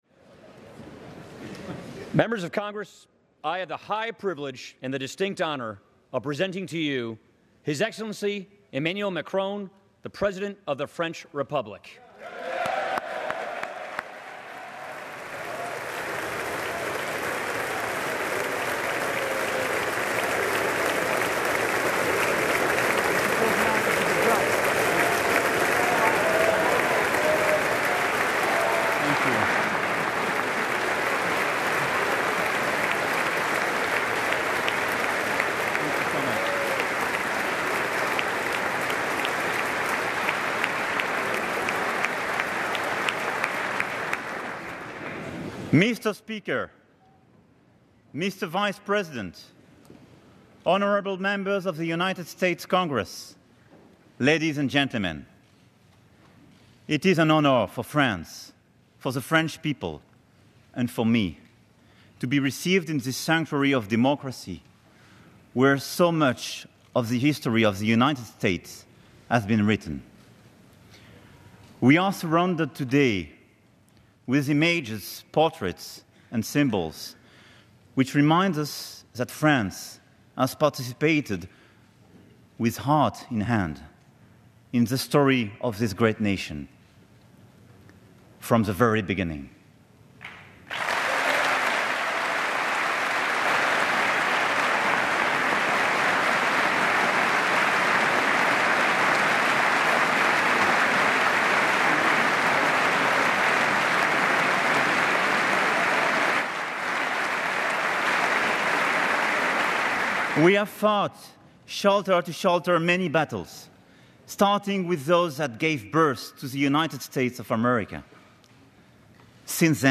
Emmanuel Macron address to joint meeting of Congress